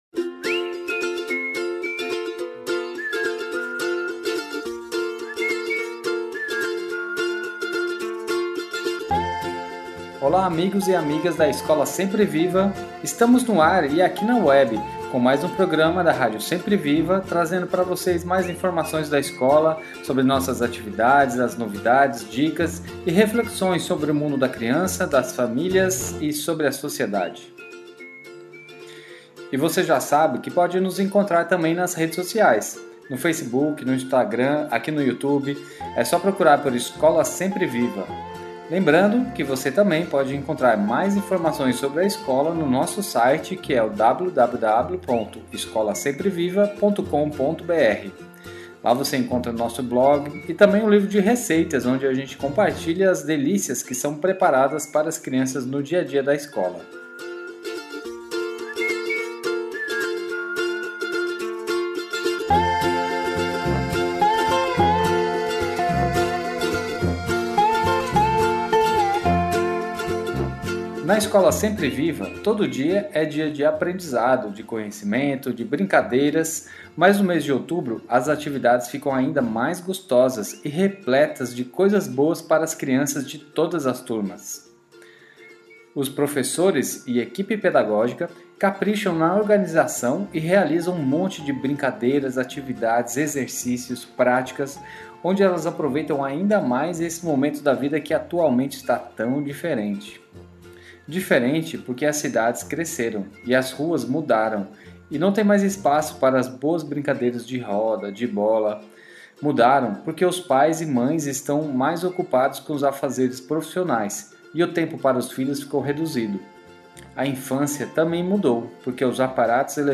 Neste programa fazemos uma reflexão sobre como está a infância do século XXI, falamos sobre a proposta pedagógica da semana da criança e as professoras falam das atividades desenvolvidas no evento.